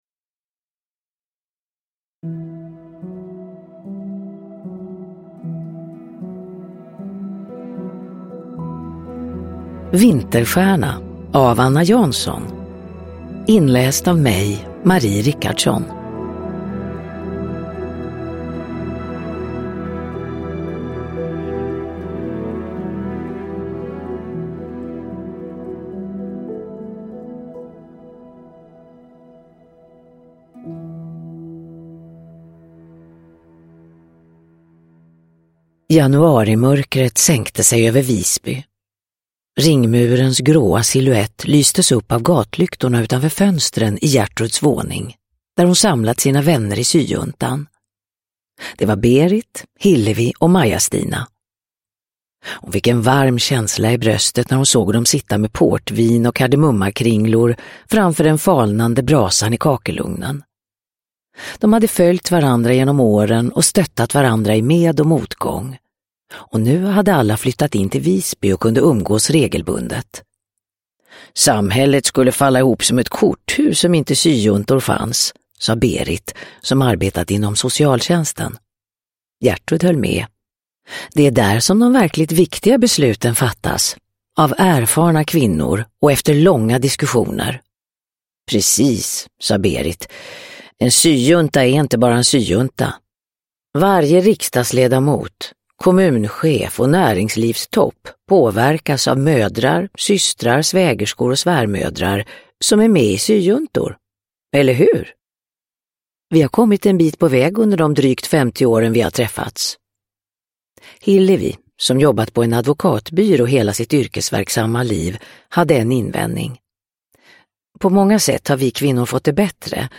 Vinterstjärna – Ljudbok – Laddas ner
Uppläsare: Marie Richardson